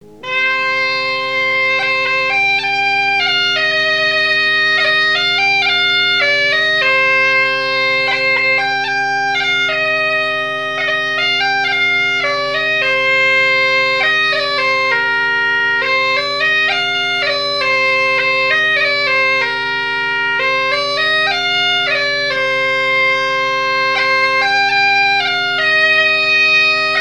Air n° 4 par Sonneurs de veuze
Airs joués à la veuze et au violon et deux grands'danses à Payré, en Bois-de-Céné
Pièce musicale inédite